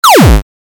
جلوه های صوتی
برچسب: دانلود آهنگ های افکت صوتی اشیاء دانلود آلبوم صدای کلیک موس از افکت صوتی اشیاء